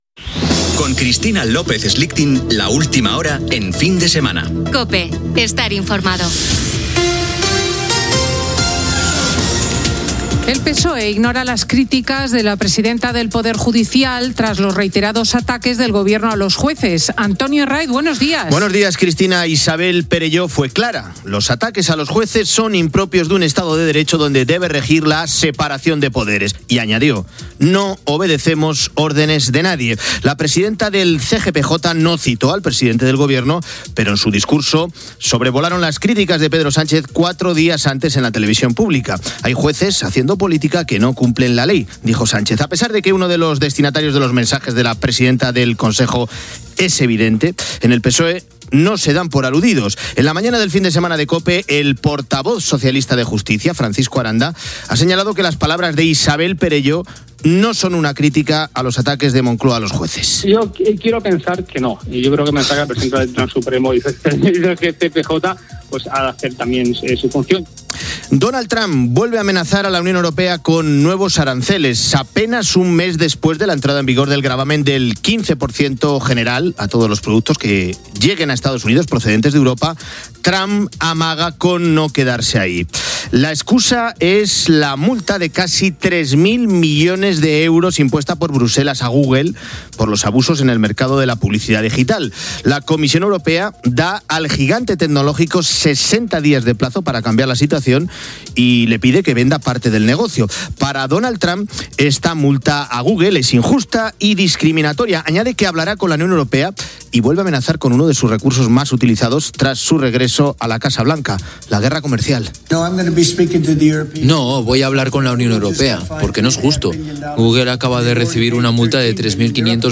Indicatiu del programa, "Última hora" amb un resum informatiu (multa a Google, Gaza, esports). Indicatiu del programa, salutació, data i presentació en la primera edició de la temporada, La cara del rei Felipe de Borbón en l'acte d'obertura de l'any judicial
Entreteniment
FM